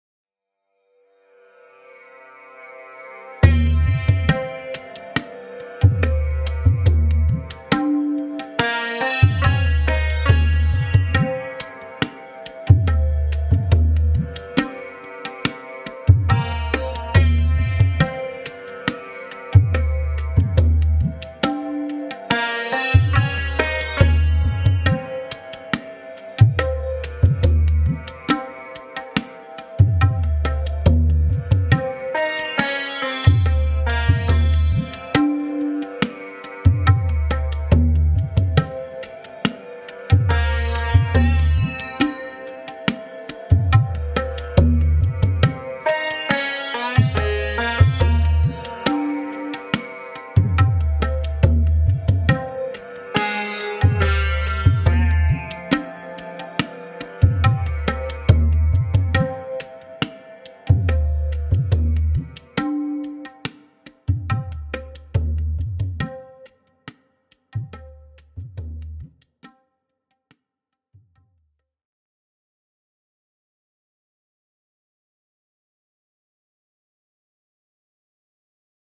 ショート暗い民族